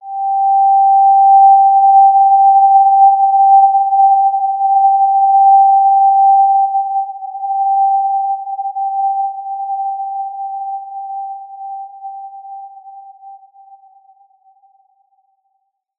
Basic-Tone-G5-mf.wav